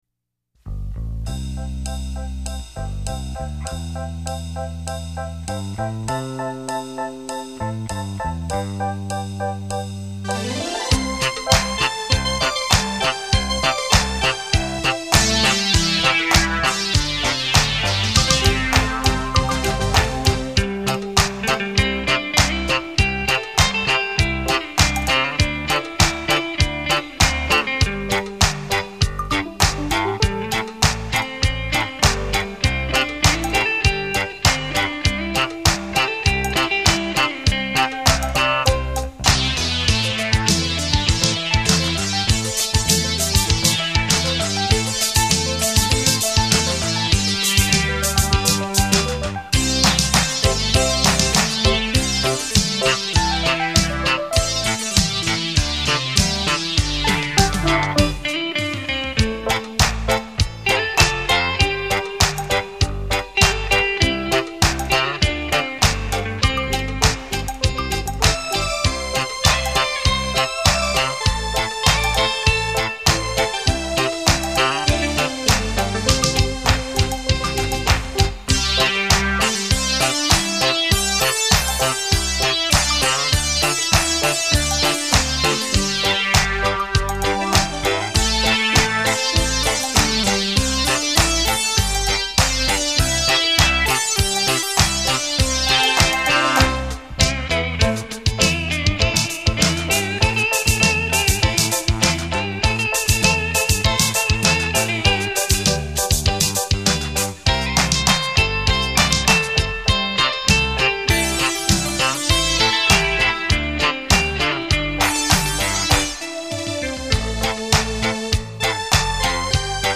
样本格式    : 44.100 Hz; 16 Bit; 立体声